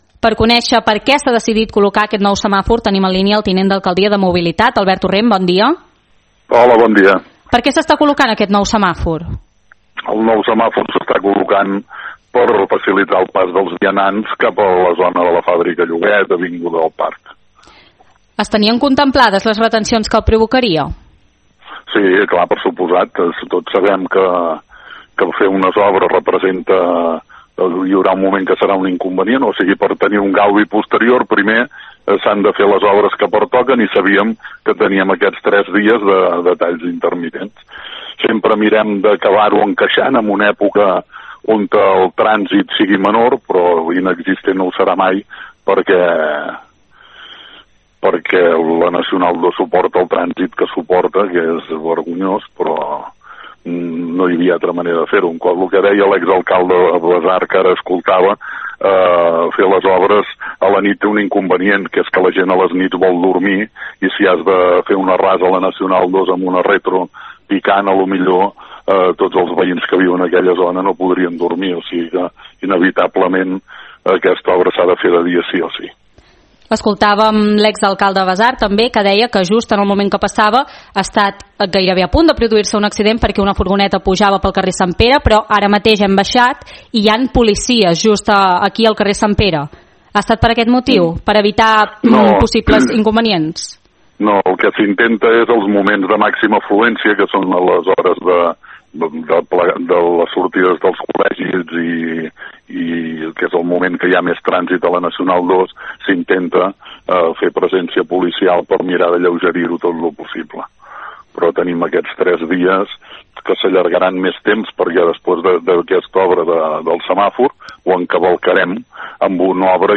A continuació podeu recuperar l’entrevista íntegra al tinent d’Alcaldia de Mobilitat, Albert Torrent.
0811-ENTREVISTA-ALBERT-TORRENT-SEMÀFOR.mp3